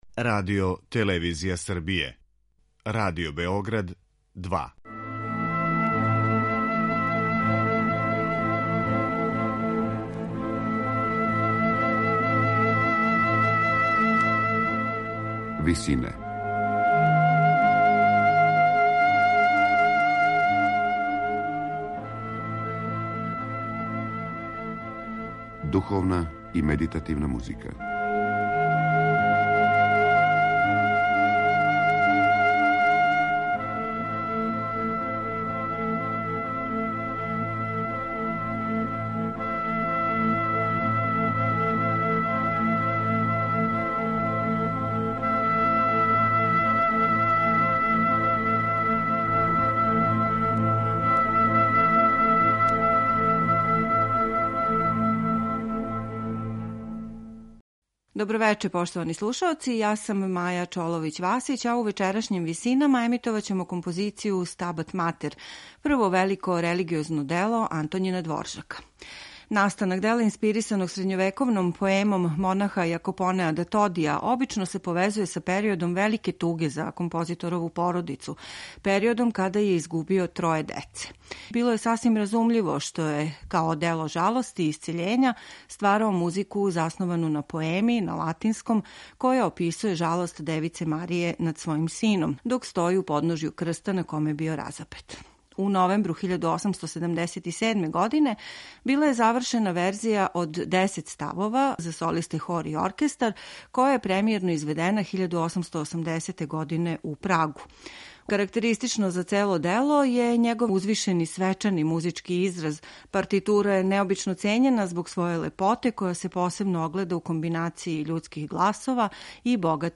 за солисте, хор и оркестар
Оно открива осећања великог бола који је чешки композитор проживљавао због губитка троје деце током 1876. године. Изабране фрагменте слушаћете у извођењу солиста, хора и оркестра Радија централне Немачке из Лајпцига, којима диригује Ендру Литон.